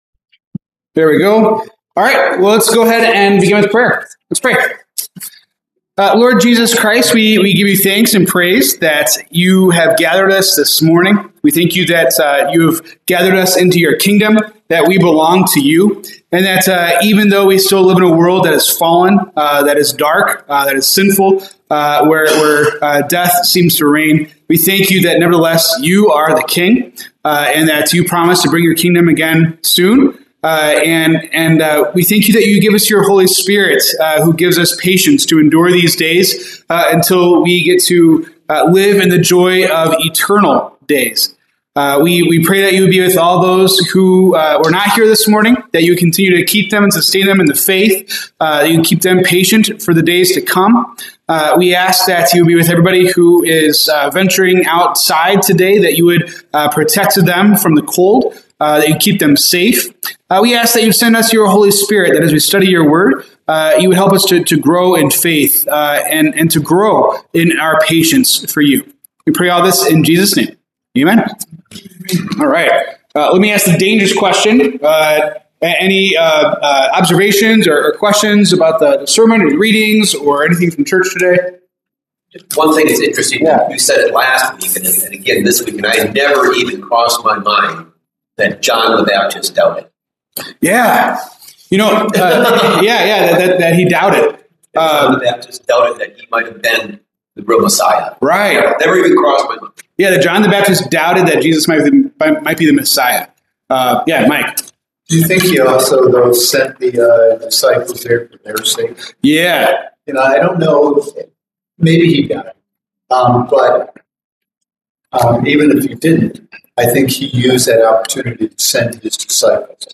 Discussion on Revelation 3:14-22.